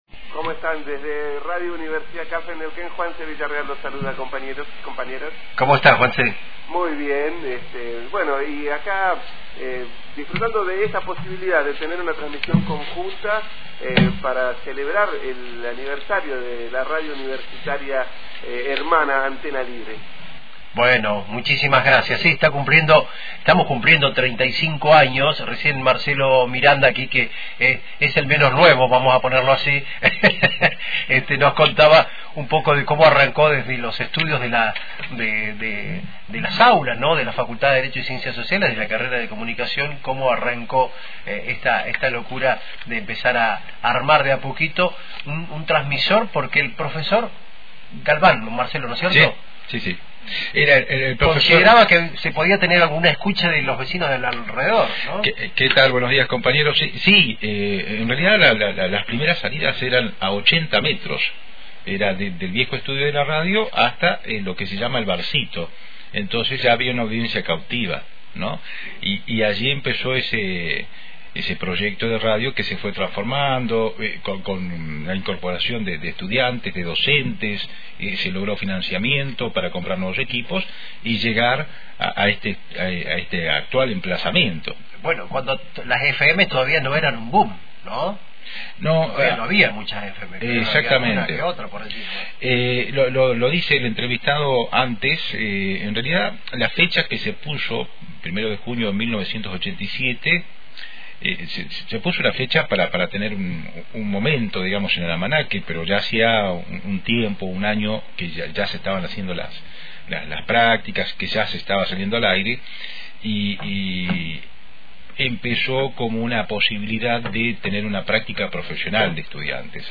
En este día de festejo, conocemos un poco sobre su historia, sus comienzos y transformaciones hasta lo que es el actual emplazamiento de la radio. Escuchá una transmisión conjunta con Radio Universidad Calf y el recuerdo de algunas de las voces históricas de «la radio de la vida».